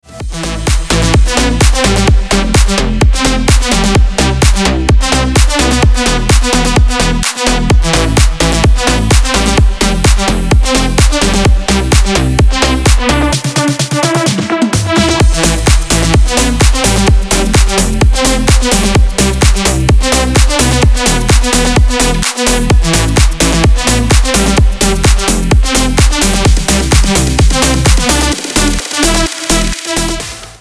Dance рингтоны